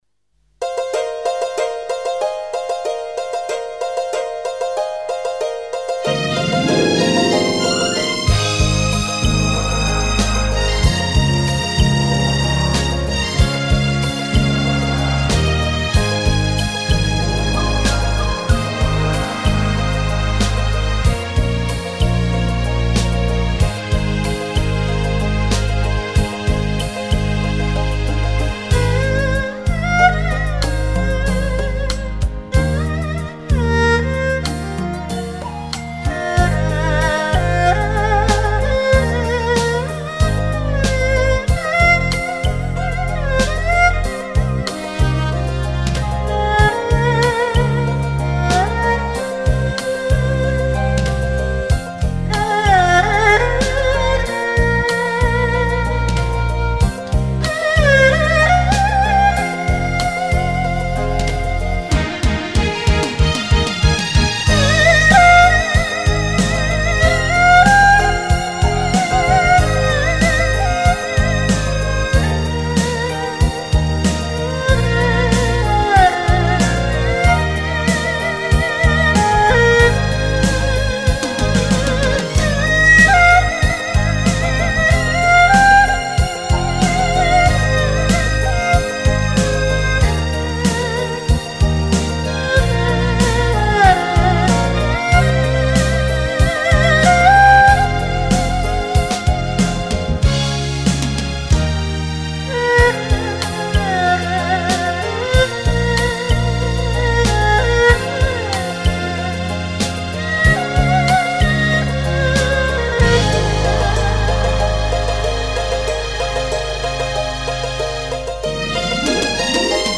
那种柔和低沉的音色时而急，时而缓，二胡那种柔中带刚的气质感受[她的古典美]